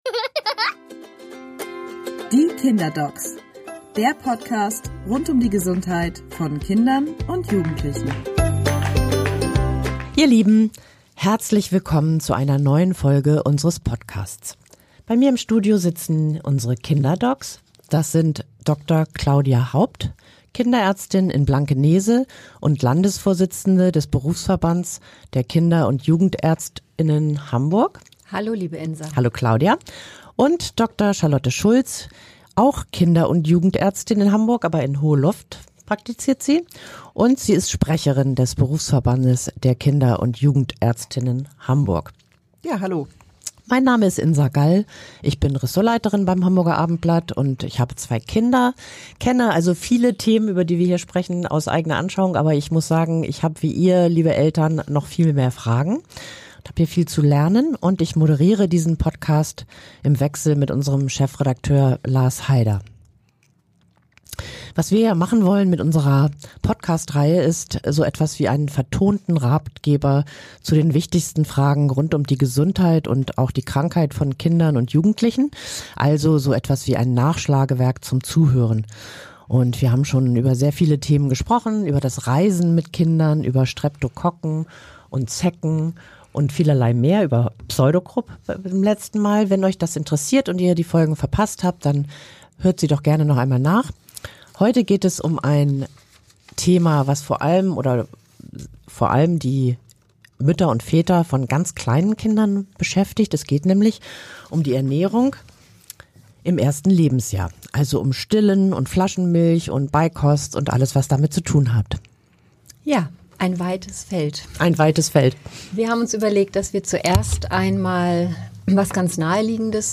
Neben den "Dos" gibt es aber auch die "Don´ts":Die Kinderärztinnen sprechen auch über Fehler, die Eltern unbedingt vermeiden sollten.